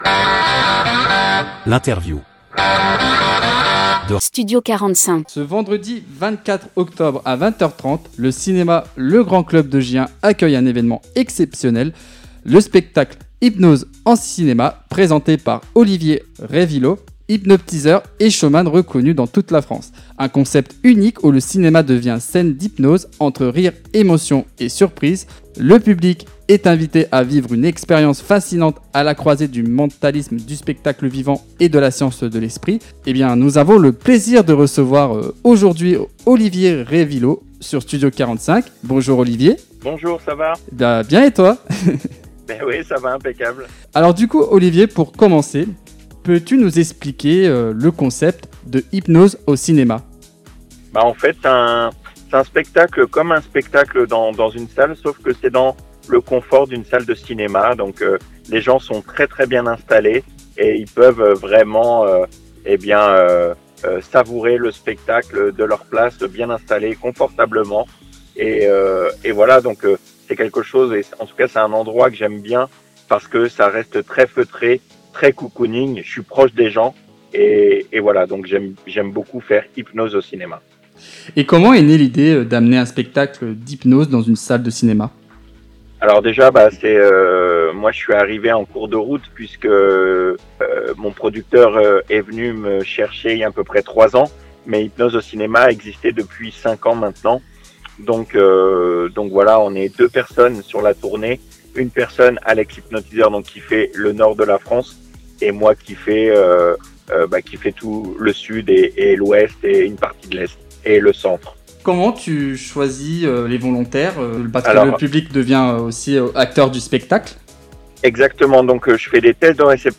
Interview Studio 45